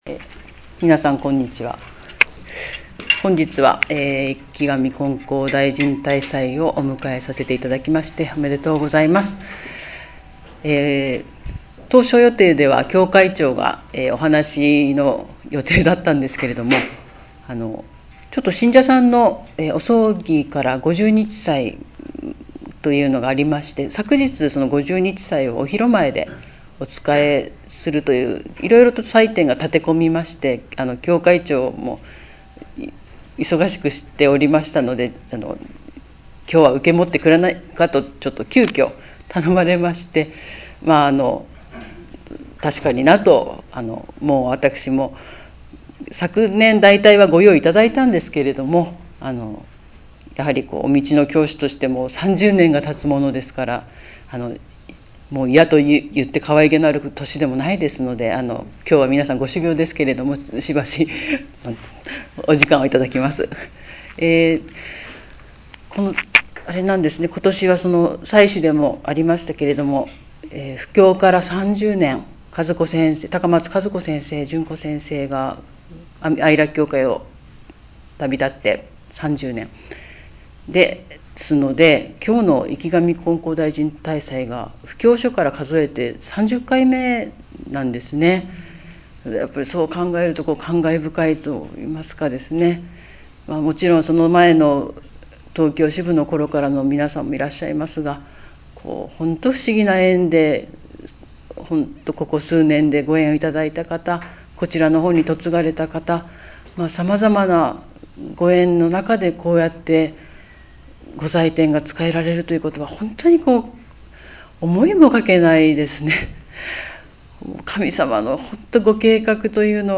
生神金光大神大祭･講話